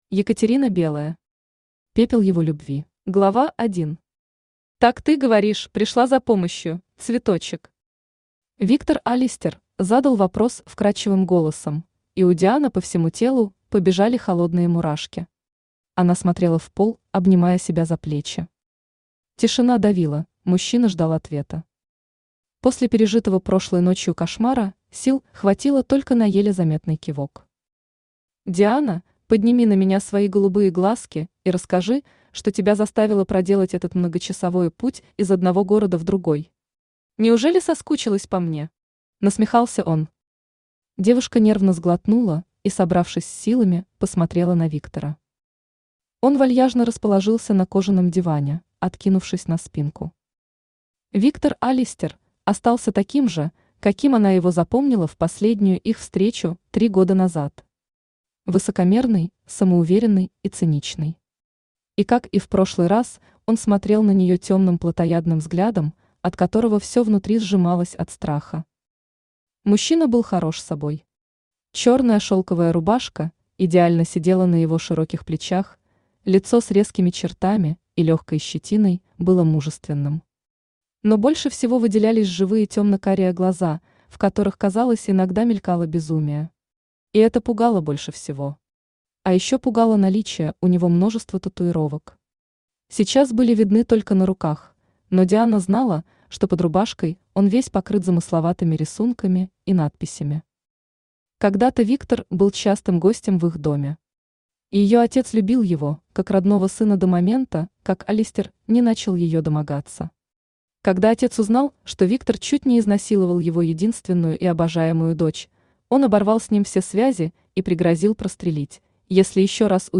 Аудиокнига Пепел его любви | Библиотека аудиокниг
Aудиокнига Пепел его любви Автор Екатерина Белая Читает аудиокнигу Авточтец ЛитРес.